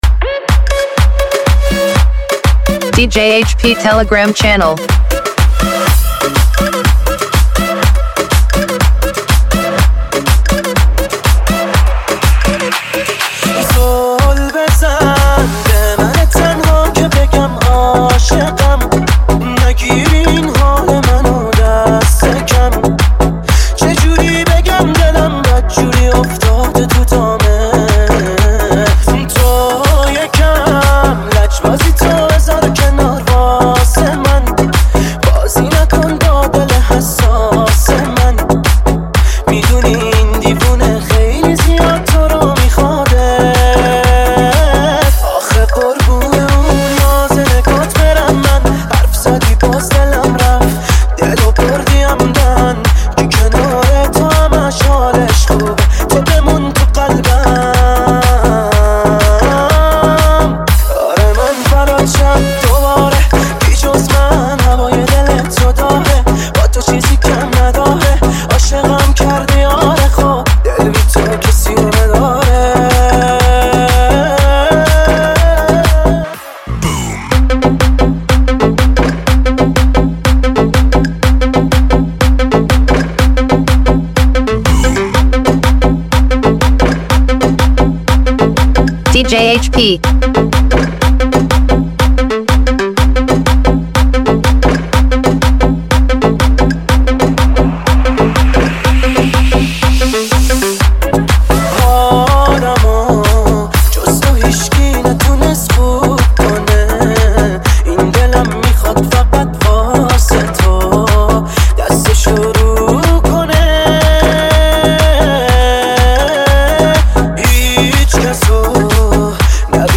ریتم شاد و کلمات پرمهرش حال آدم رو حسابی خوب می‌کنه.